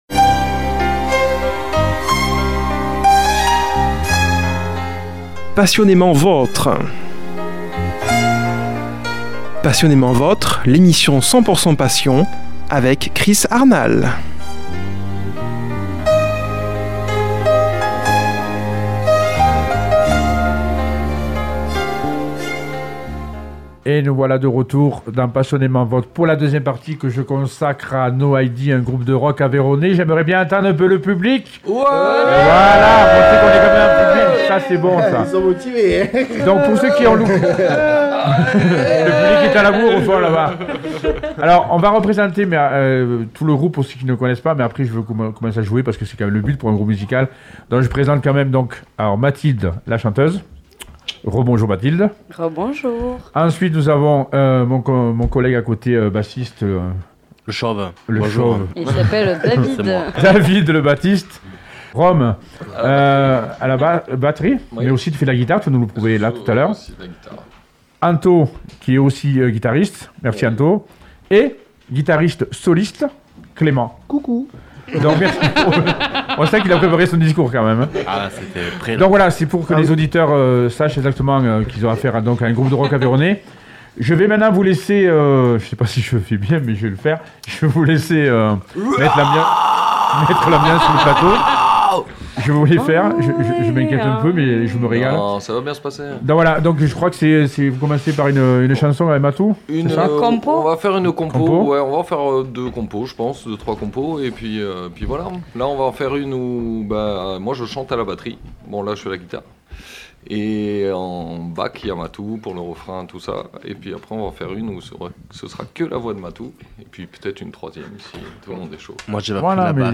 Ambiance survoltée ..fous rires...impro..solo de guitare endiable....Un moment mémorable à écouter et partager